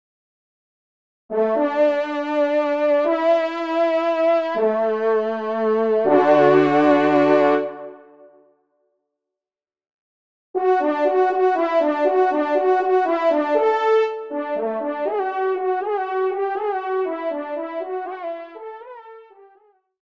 Genre : Fantaisie Liturgique pour quatre trompes
Pupitre 1° Tromp